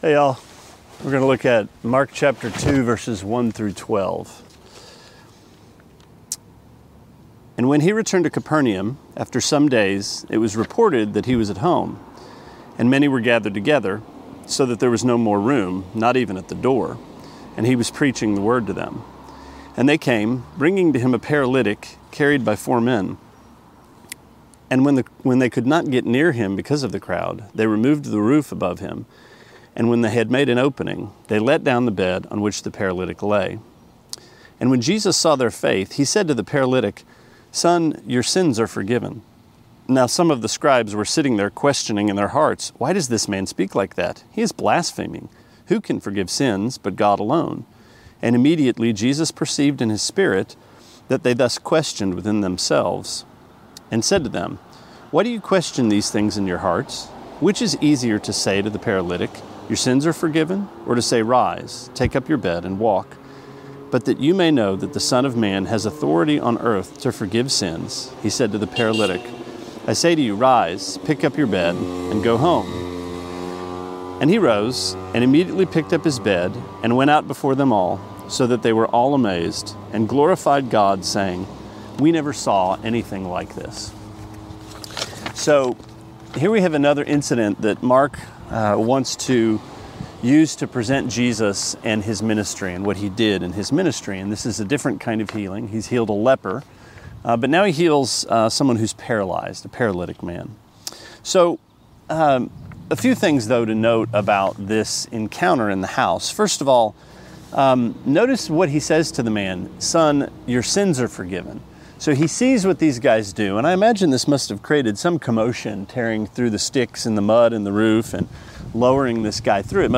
Sermonette 6/17: Mark 2:1-12: Rend the Ceiling